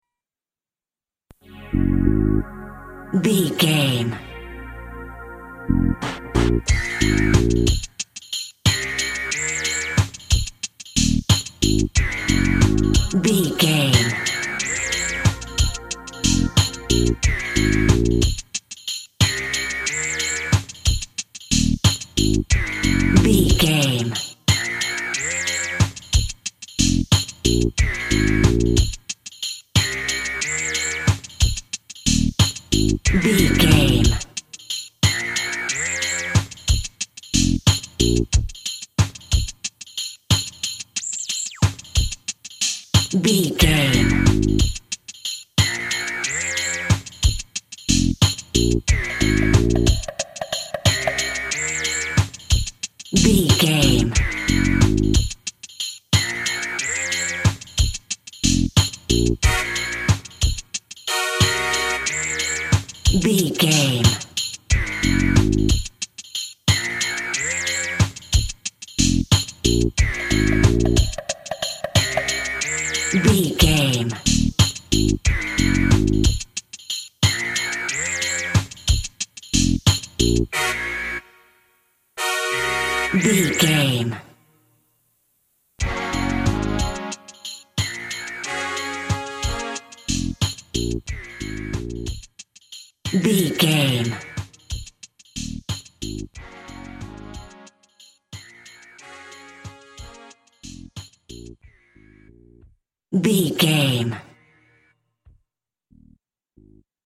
Hip Hop for Driving.
Aeolian/Minor
B♭
synth lead
synth bass
hip hop synths